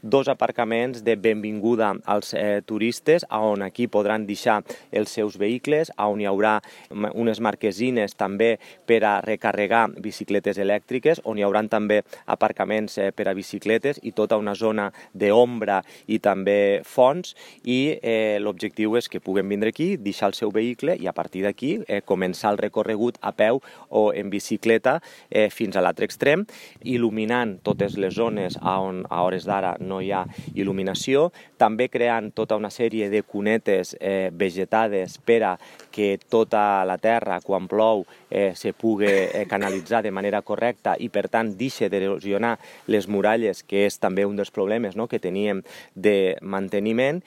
Un itinerari cicloturístic de gairebé tres quilòmetres  que connectarà la torreta de Remolins amb el Fortí d’Orleans, a la Simpàtica. L’alcalde de Tortosa, Jordi Jordan, ha destacat que es tracta d’una obra molt important, que té com a finalitat posar en valor els sis quilòmetres de muralles que té la ciutat i convertir-les en un actiu turístic….
Jordan-CircuitVerd_actuacio.mp3